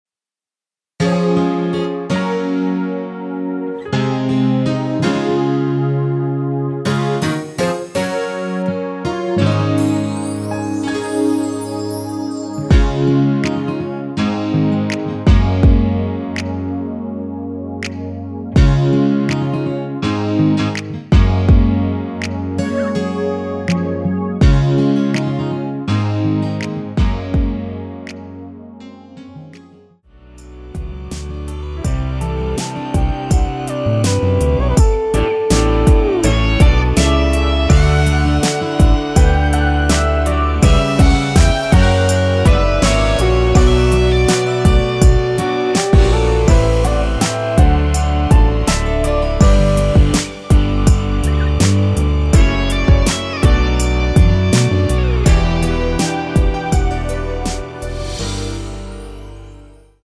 Db
◈ 곡명 옆 (-1)은 반음 내림, (+1)은 반음 올림 입니다.
앞부분30초, 뒷부분30초씩 편집해서 올려 드리고 있습니다.
중간에 음이 끈어지고 다시 나오는 이유는